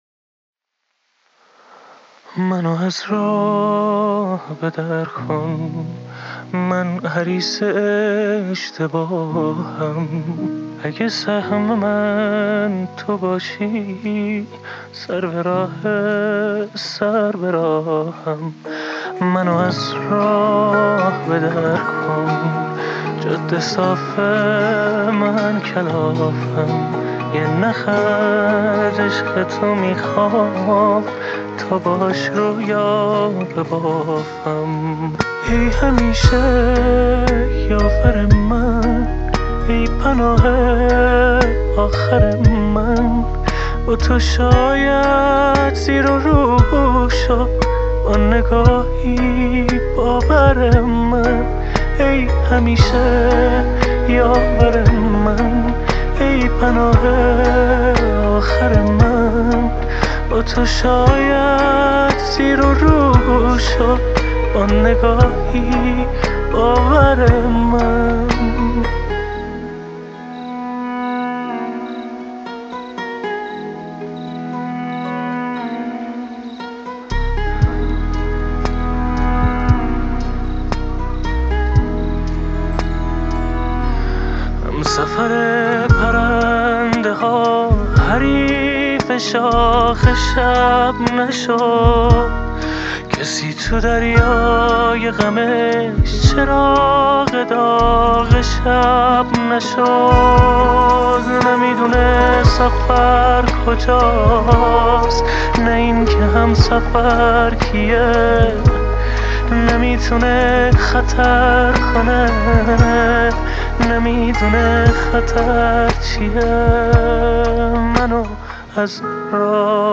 موزیک عاشقانه